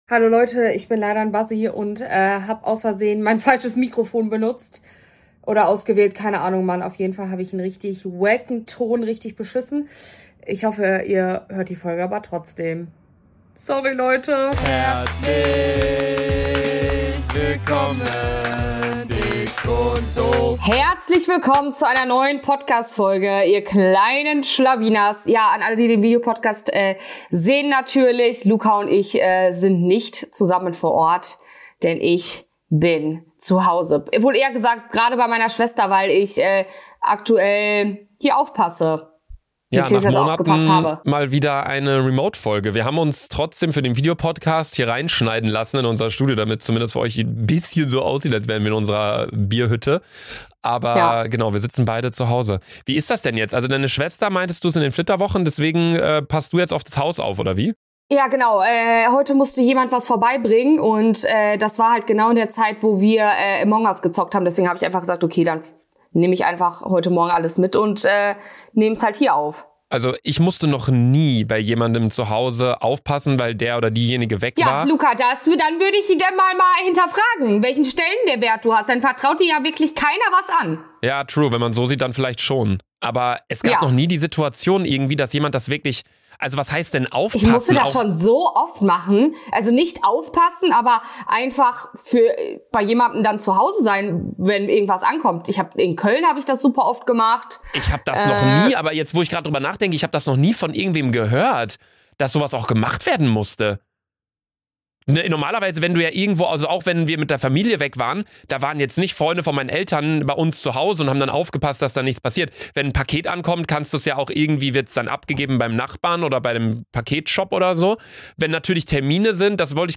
musste nach Langem mal wieder eine Folge von zuhause aufgenommen werden
macht euch also gefasst auf eine geballte Ladung Ohrenkrebs & vorbeilaufende Squirrel...